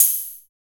HOUSE OHH.wav